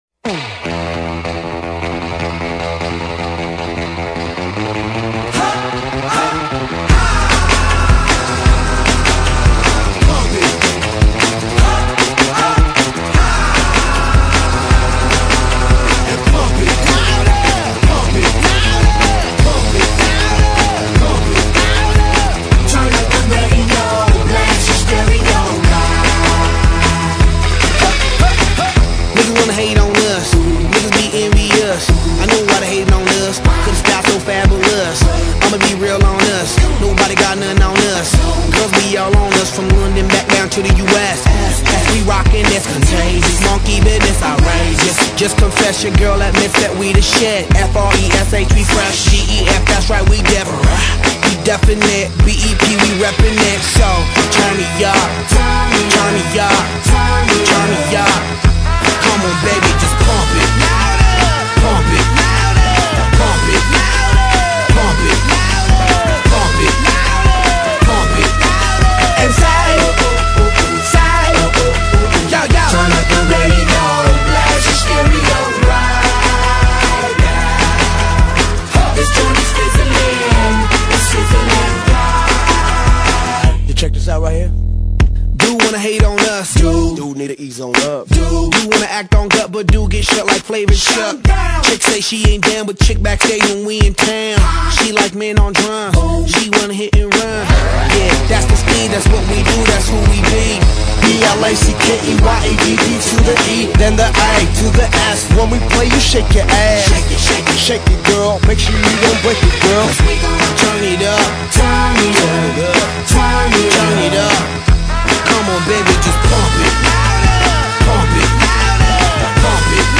风格: Hip-Hop